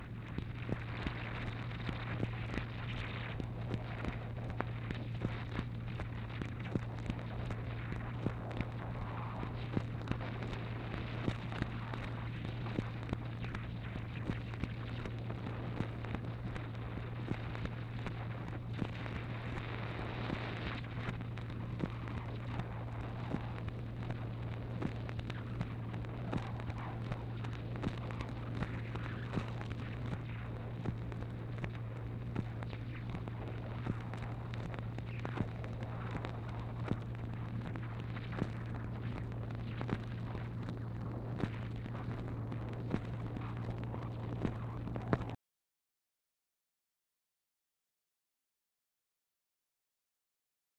MACHINE NOISE, March 30, 1964
Secret White House Tapes | Lyndon B. Johnson Presidency